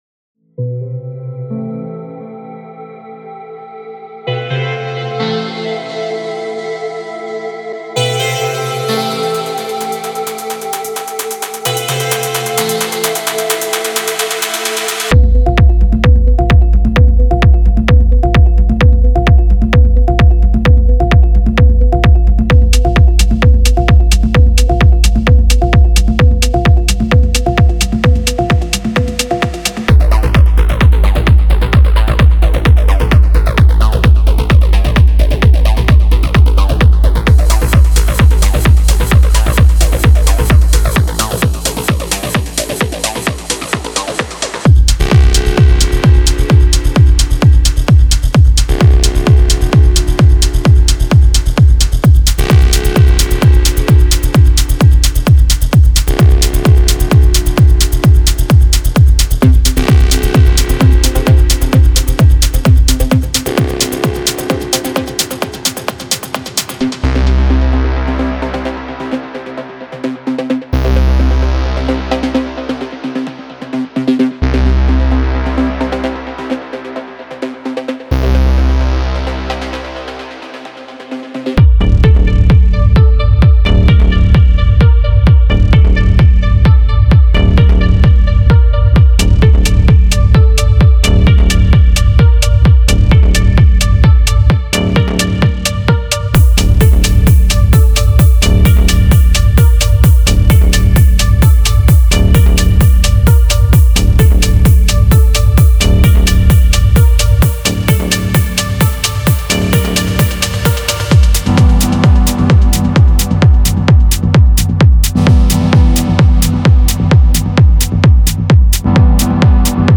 Genre:Melodic Techno
デモサウンドはコチラ↓